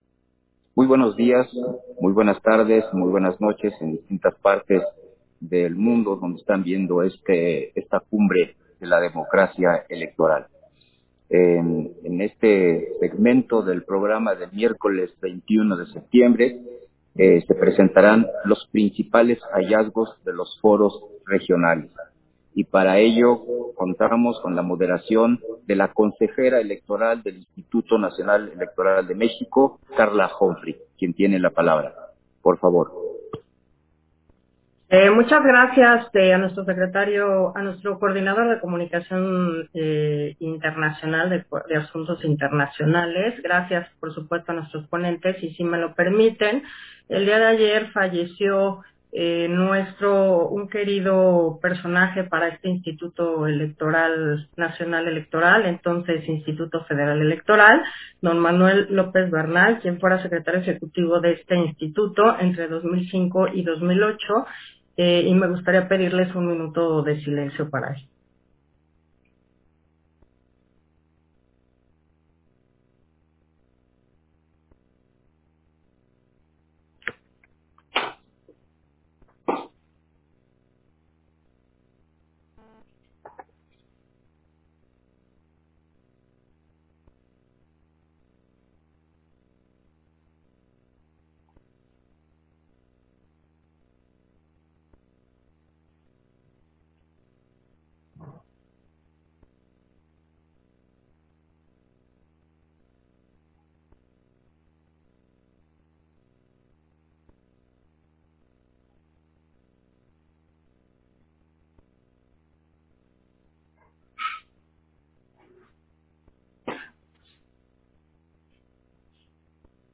210922_AUDIO_PANEL-PRINCIPALES-HALLAZGOS-DE-LOS-FOROS-REGIONALES
Versión estenográfica del panel: Principales hallazgos de los Foros Regionales, en el marco del 2º día de la Cumbre Global de la Democracia Electoral